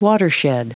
Prononciation du mot watershed en anglais (fichier audio)
Prononciation du mot : watershed